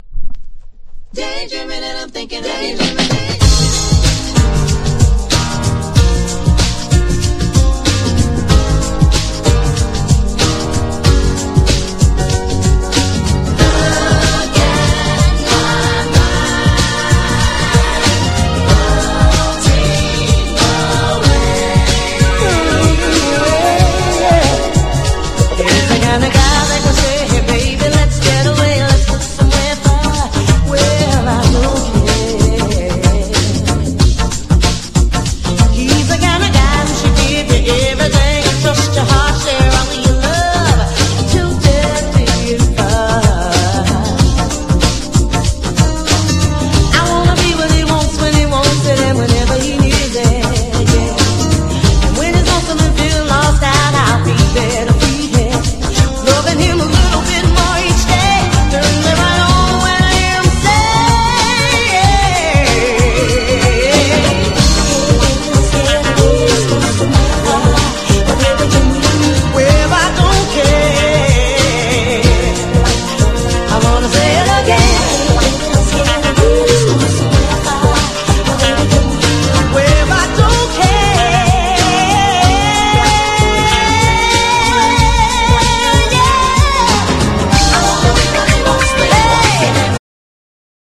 ダンサブルなトラックに
UK SOUL / ACID JAZZ / GRAND BEAT